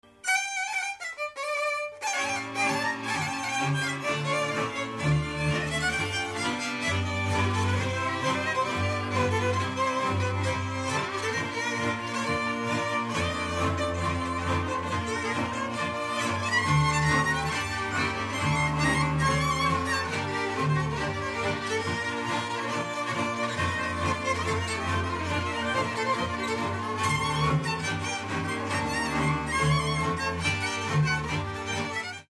Dallampélda: Hangszeres felvétel
Erdély - Kolozs vm. - Bánffyhunyad
hegedű
kontra
cselló
Stílus: 4. Sirató stílusú dallamok